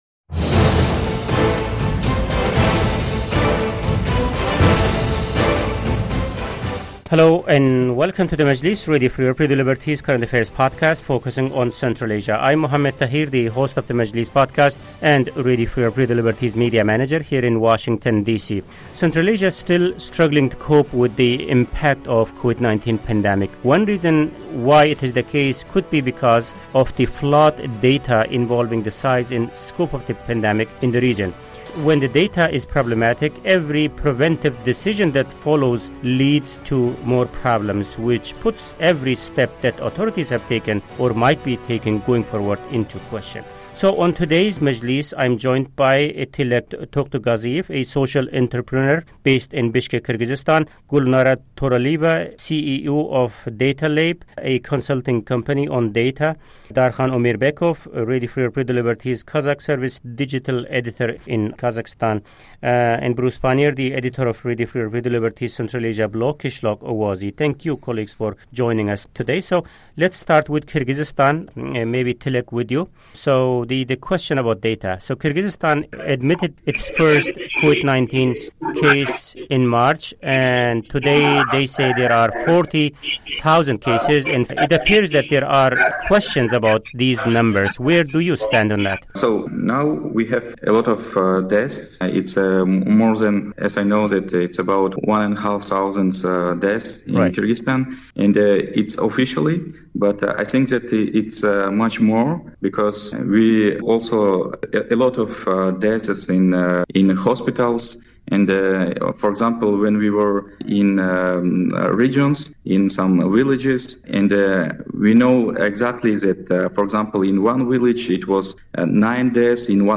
moderates a discussion on the different methods Central Asian governments are using to count the number of coronavirus cases and deaths.